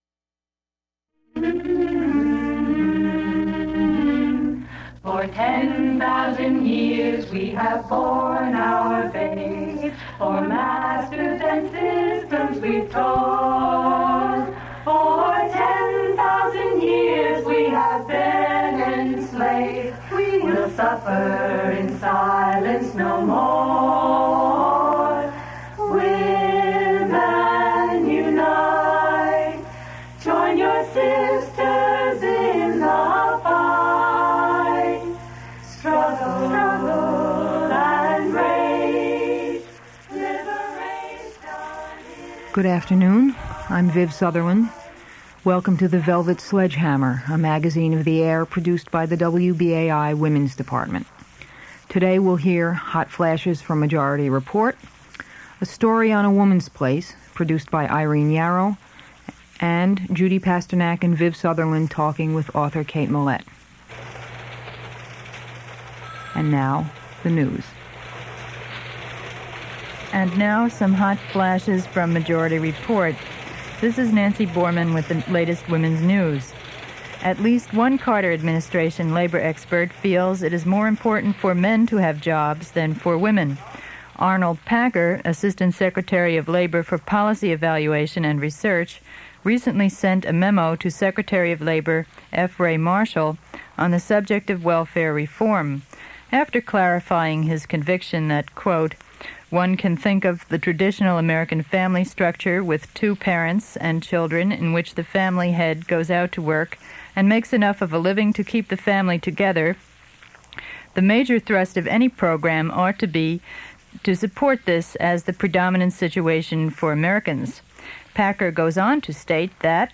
Lesbian radio